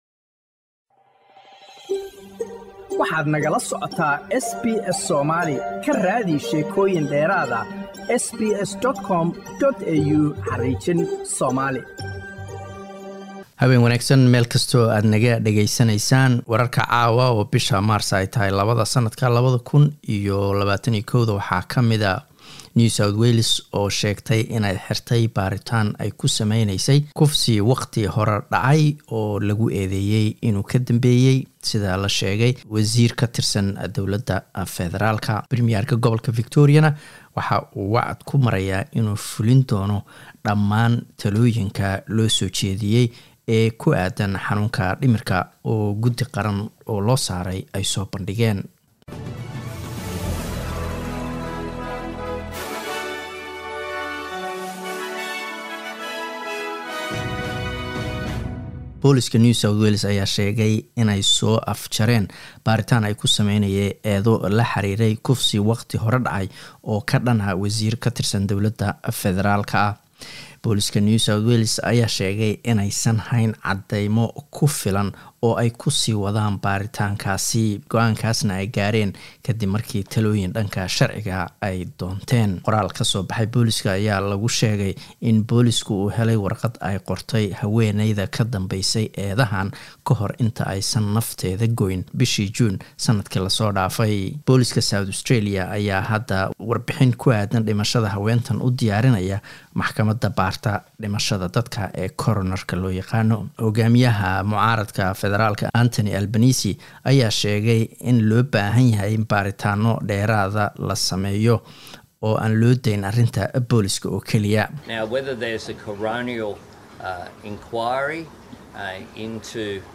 Wararka SBS Somali Talaado 02 Maarso